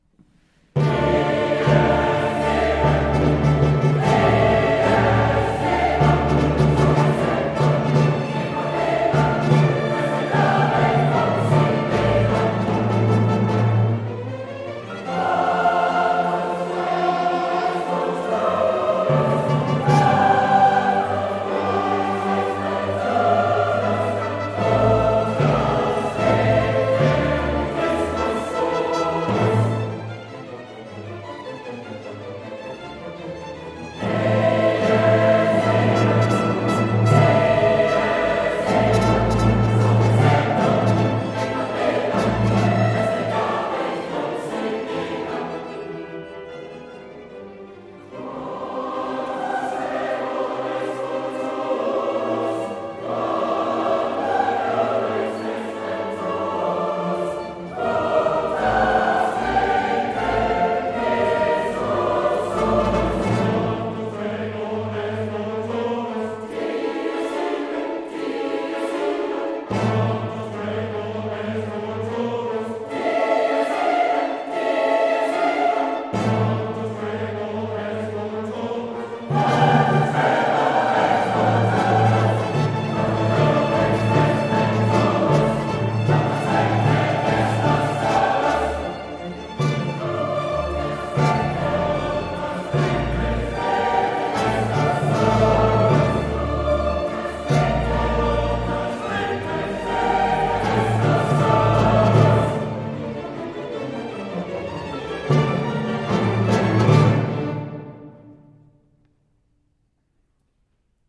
Dies Irae from a complete performance of Mozart’s Requiem recorded live in Lichfield Cathedral with Staffordshire County Chorus and Staffordshire Symphony Orchestra, featured on BBC Radio 4 in 2016